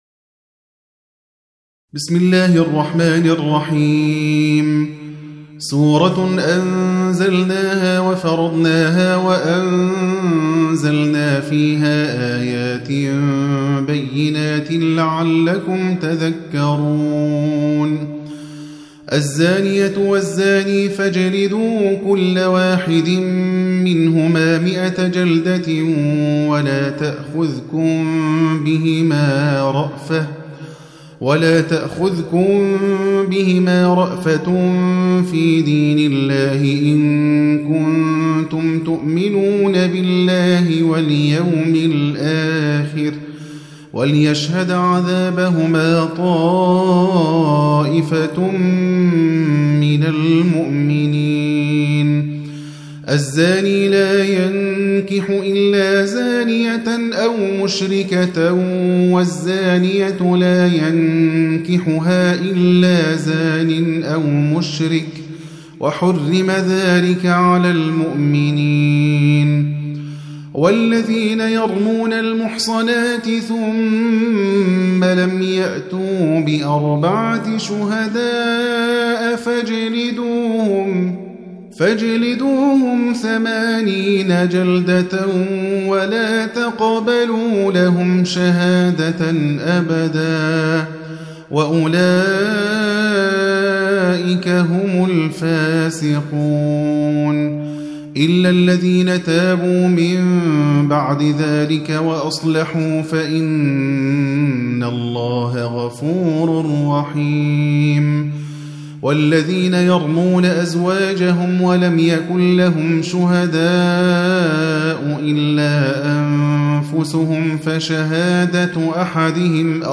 Surah Sequence تتابع السورة Download Surah حمّل السورة Reciting Murattalah Audio for 24. Surah An-N�r سورة النّور N.B *Surah Includes Al-Basmalah Reciters Sequents تتابع التلاوات Reciters Repeats تكرار التلاوات